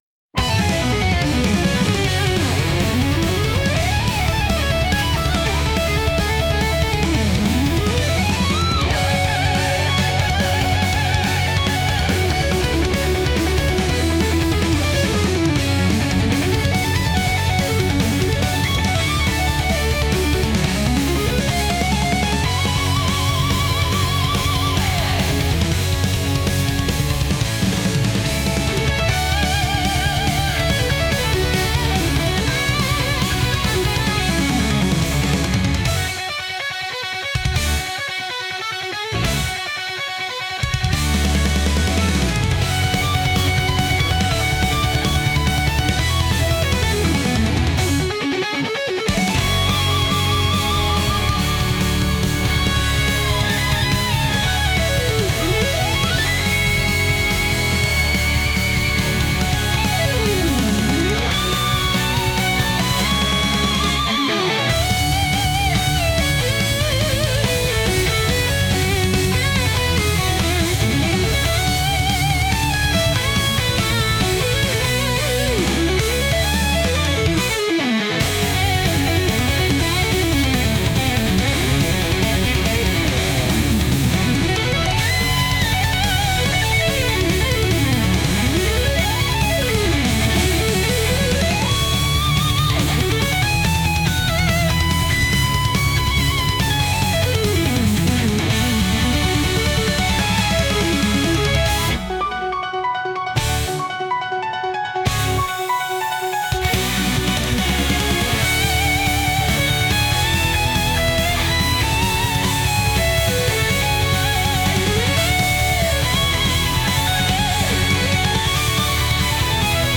全力で物事に取り組むためのBGM